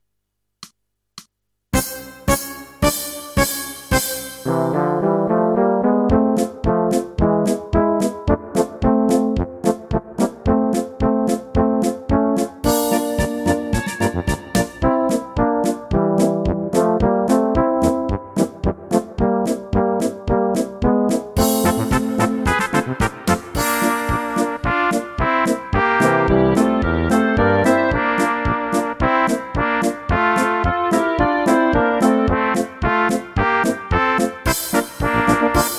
Rubrika: Národní, lidové, dechovka
- polka